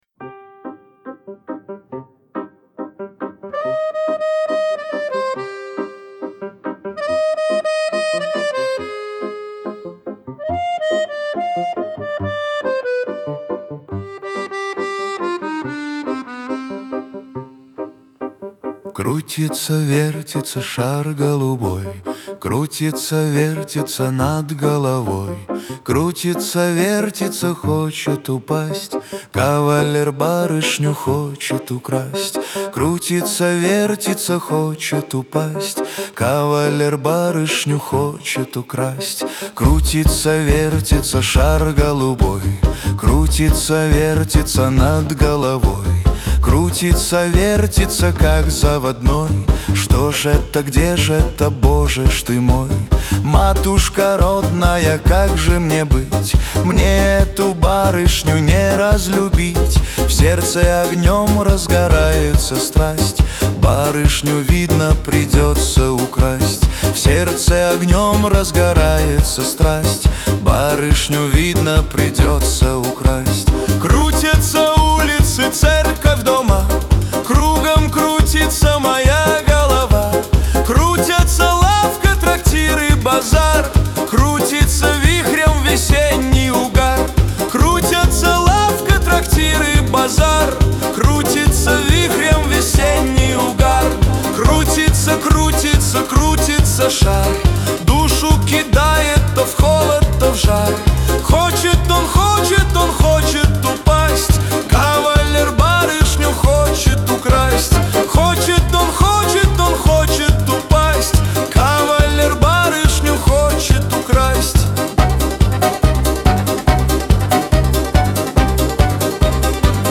Кавер-версия
Шансон